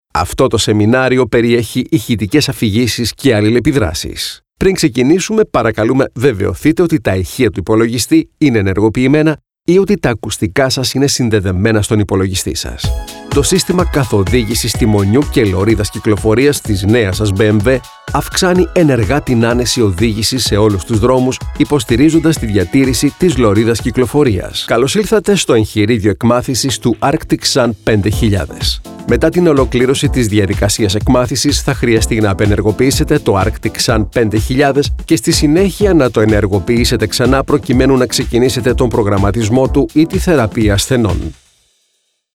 Sprechprobe: eLearning (Muttersprache):
Native Greek voice renown for its versatility.
Own a high end professional recording studio with state of the art sound & equipment. A voice that comes out warm and engaging in narrations young & fresh or smooth honest & persuasive in commercials & promos.
Studio: Neumann & Rode microphones TC Electronic & RME audio interface UA & Focusrite analog mic preamps.